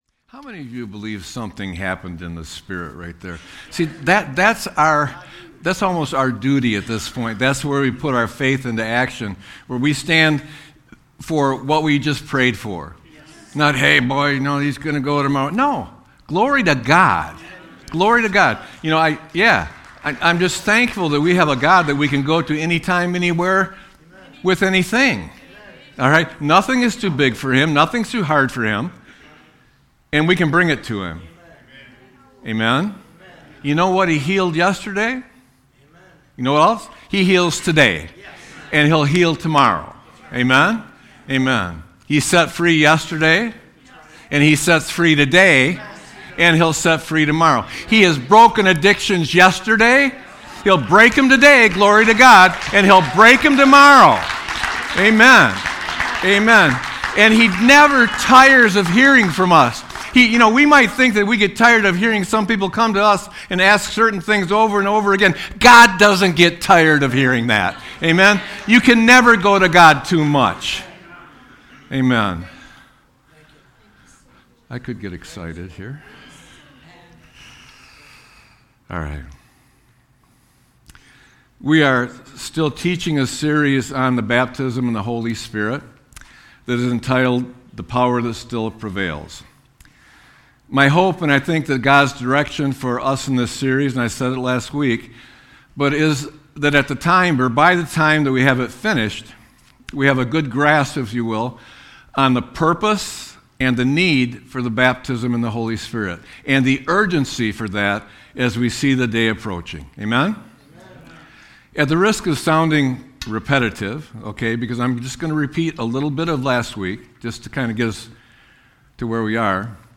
Sermon-2-22-26.mp3